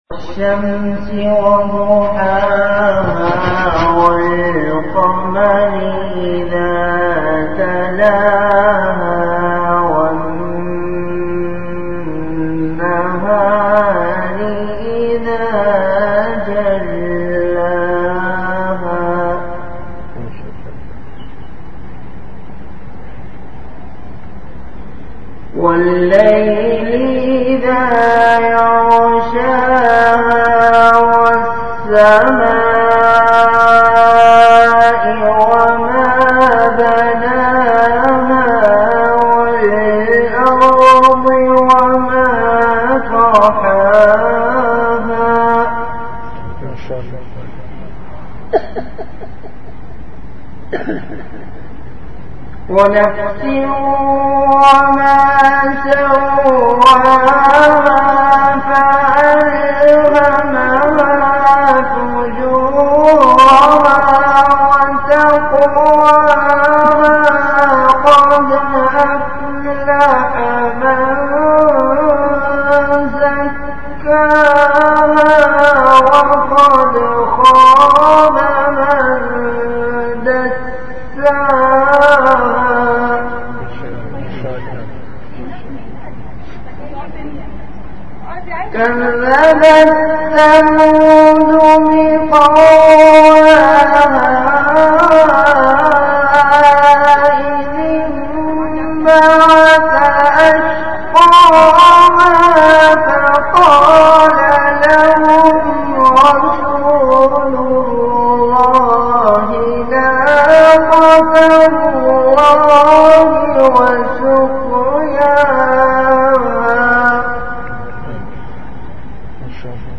Delivered at Home.
Bayanat